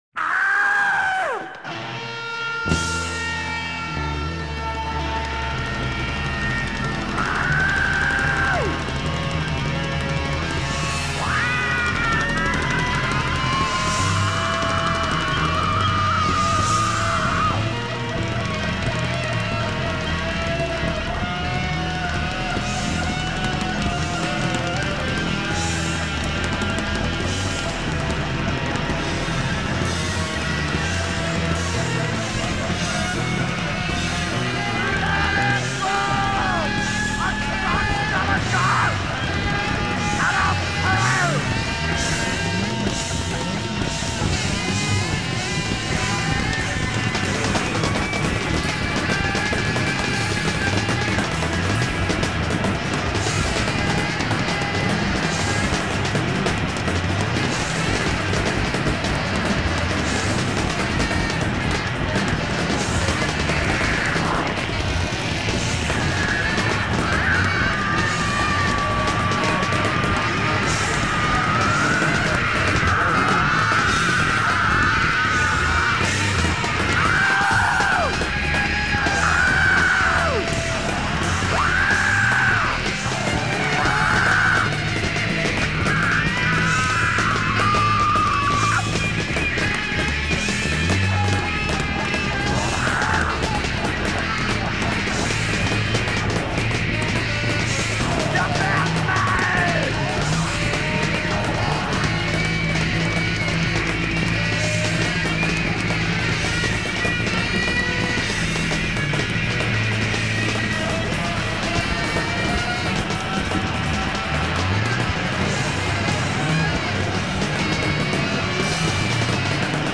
INSTRUMENTAL & SCREAMING